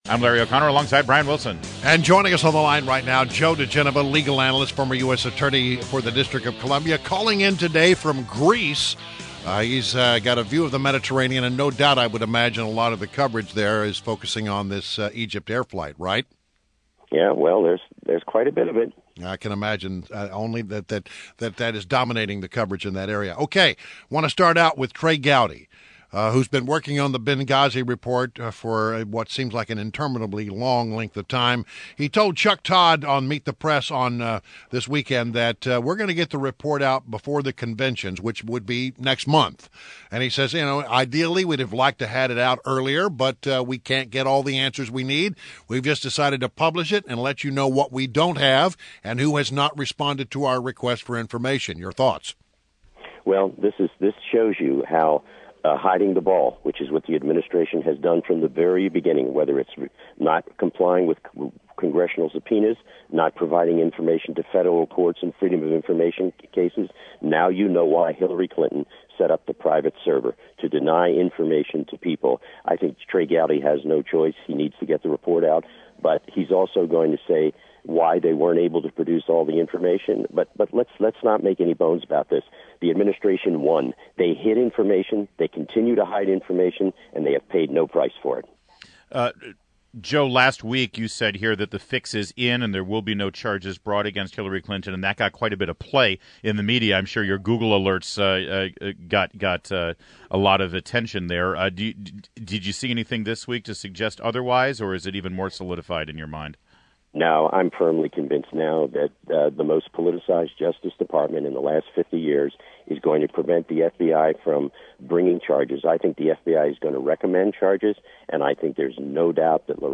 INTERVIEW -- JOE DIGENOVA - legal analyst and former U.S. Attorney to the District of Columbia